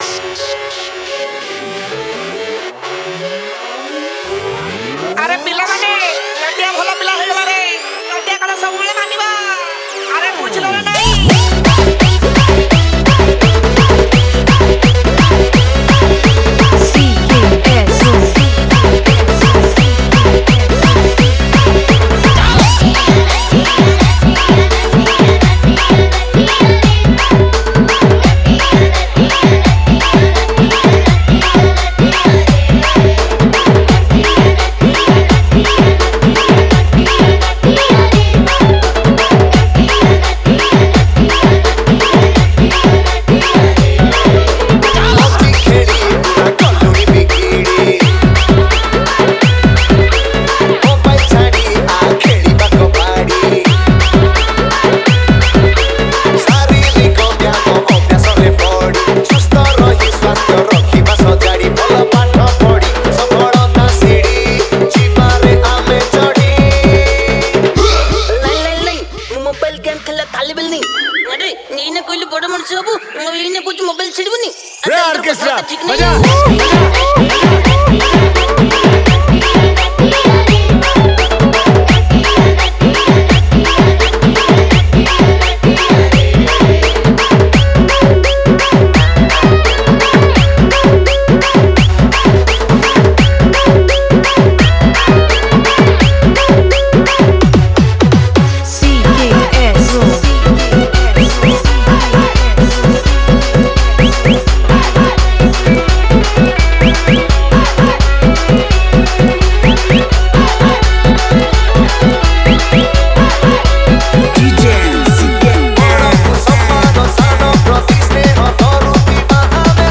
Category:  Odia New Dj Song 2019